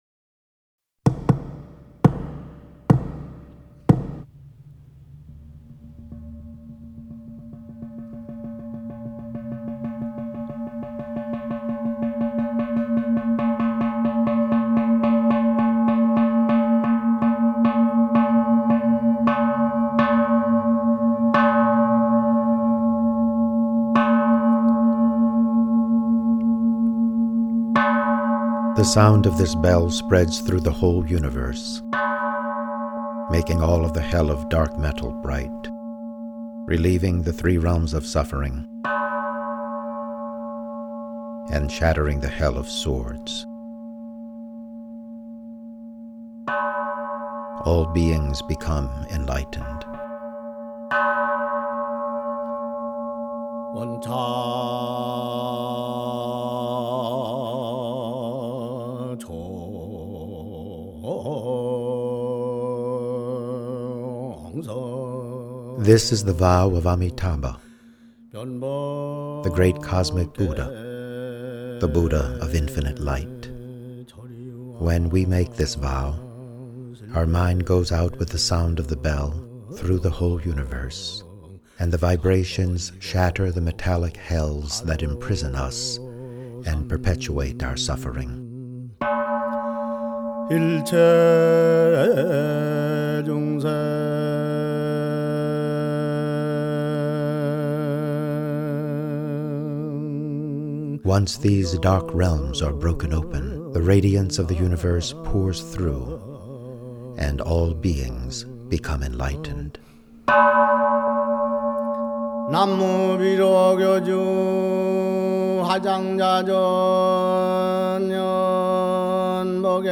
Audio Tour - Stop Look Listen